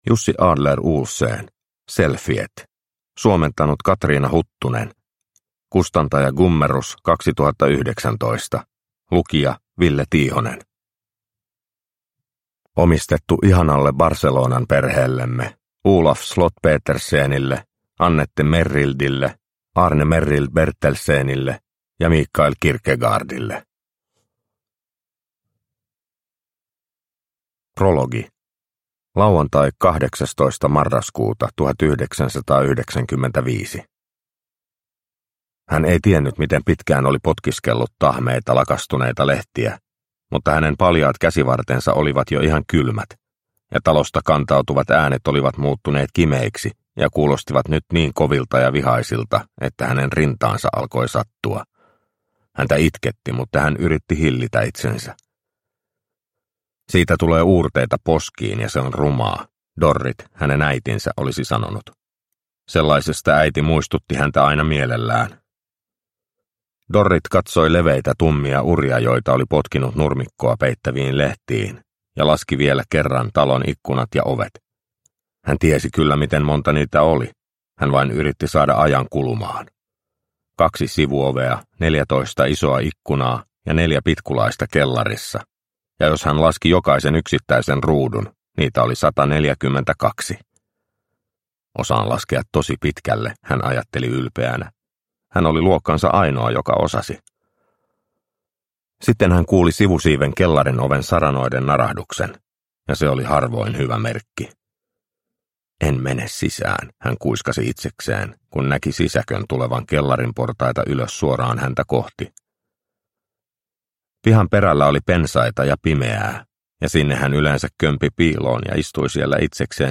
Selfiet – Ljudbok – Laddas ner